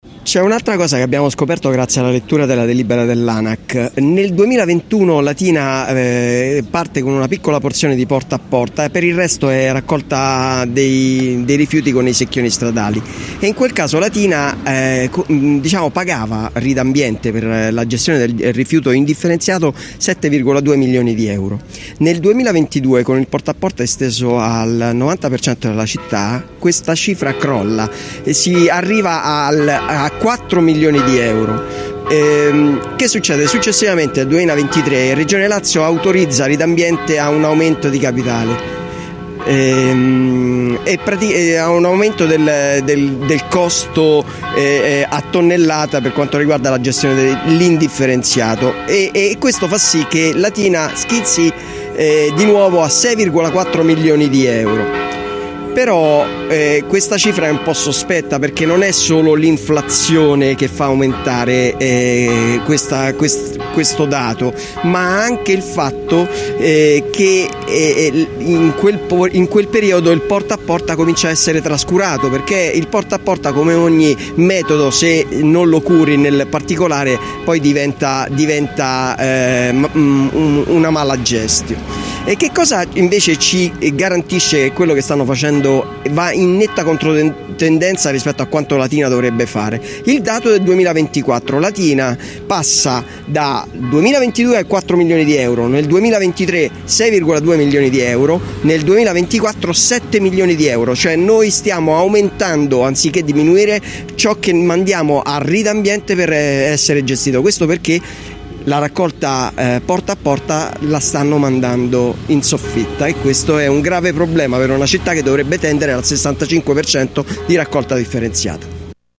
In una conferenza stampa che si è tenuta questa mattina nella sede di via Cattaneo, la capogruppo del movimento 5 stelle Maria Grazia Ciolfi,  quella del Pd Valeria Campagna, il capogruppo di Lbc Dario Bellini e il capogruppo di Latina per 2032 Nazareno Ranaldi hanno affrontato la questione che ha portato da due anni a questa parte a puntuali scontri in consiglio comunale.